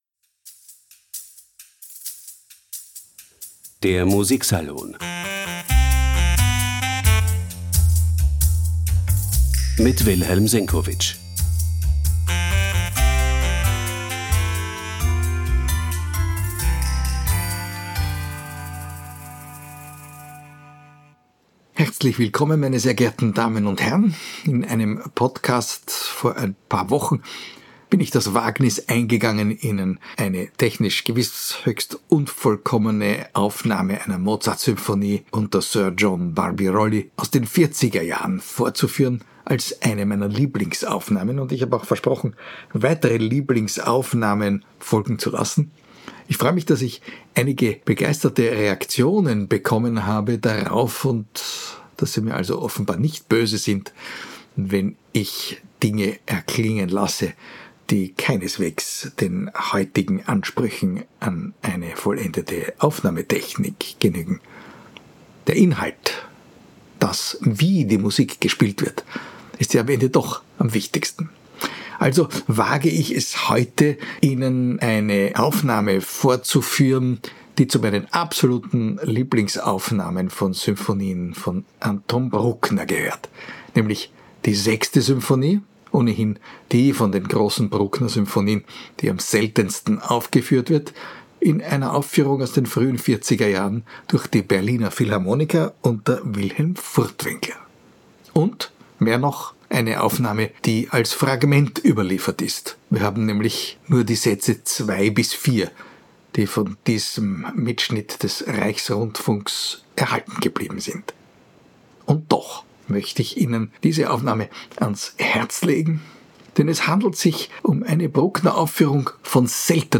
Von der aufregendsten Aufführung der selten gespielten Sechsten Symphonie Anton Bruckners existiert nur ein Fragment. Überdies stammt es aus den Vierzigerjahren, klingt also technisch reichlich angegraut.